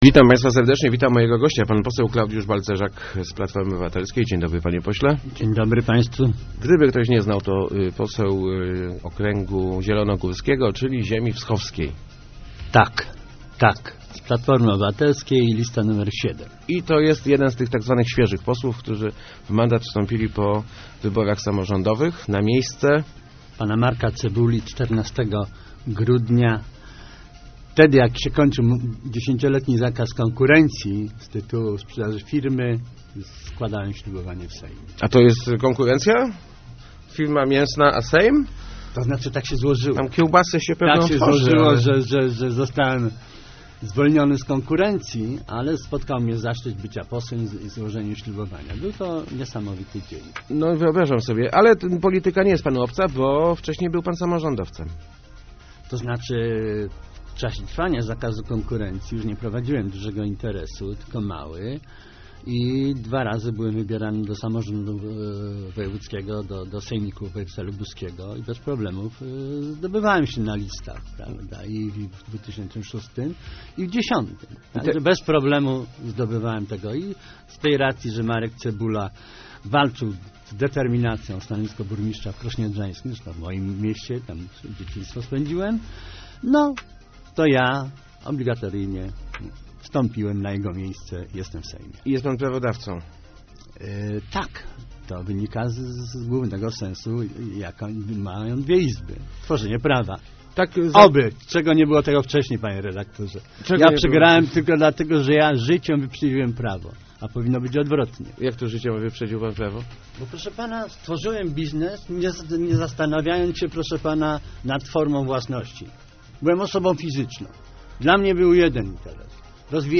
Nie do końca udało nam się pomóc przedsiębiorcom - mówił w Rozmowach Elki poseł PO Klaudiusz Balcerzak. Jego zdaniem pomysły typu "jedno okienko" czy "przyjazne państwo" to działania pozorne, które powinno zastąpić uchwalanie przyjaznego przesiębiorcom prawa.